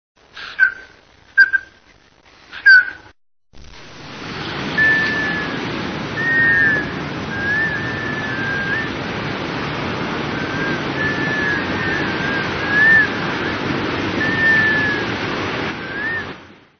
Black Scoter
Groups of Black Scoters often can be located by the constant mellow, plaintive whistling sound of the males.
Bird Sound
Wings make whistling noise in flight. Male gives clear whistle in courtship. Female give grating "kraaa."
BlackScoter.mp3